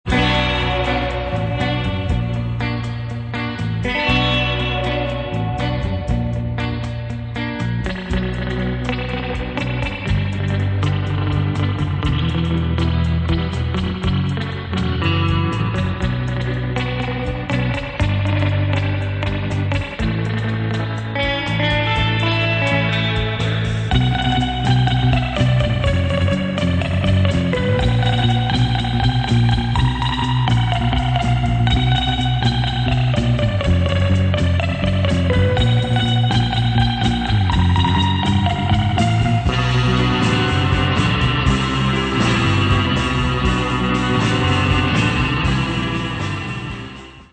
relaxed slow instr.